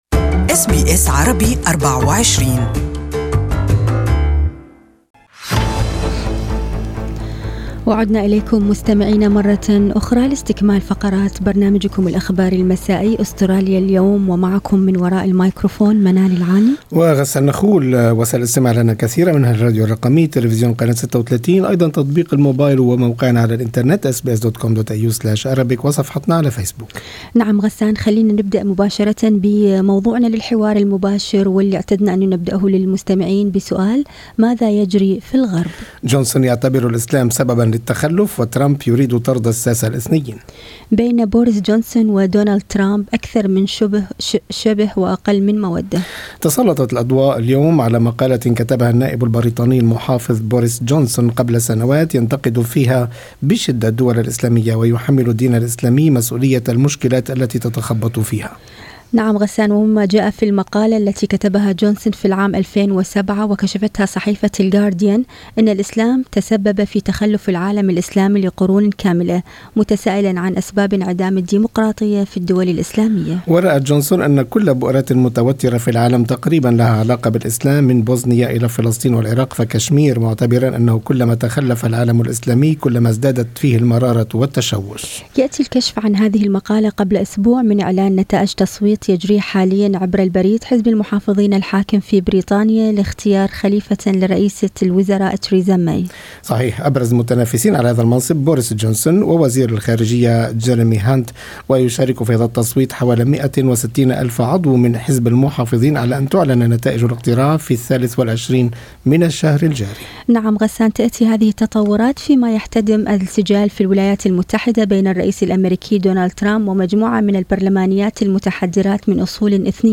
المزيد عن هذا الموضوع في حديث مع سماحة مفتي أستراليا الدكتور ابراهيم أبو محمد. استمعوا إلى المقابلة عبر الرابط الصوتي أعلاه.